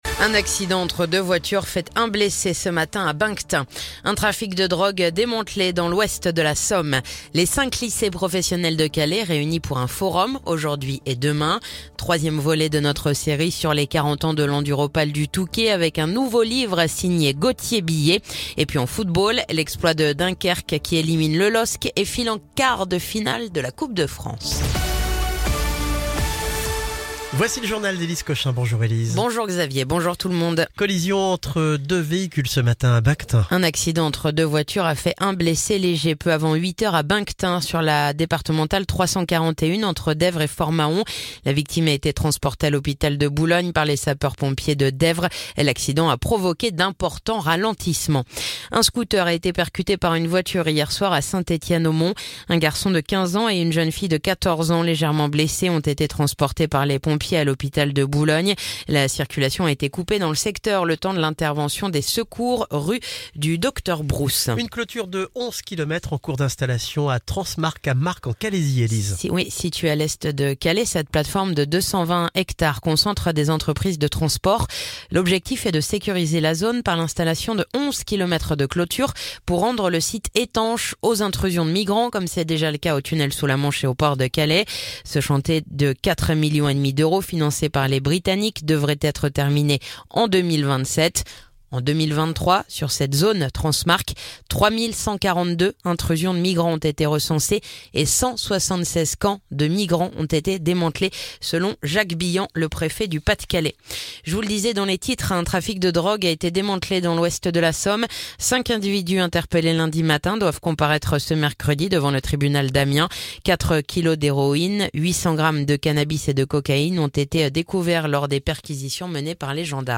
Le journal du mercredi 5 février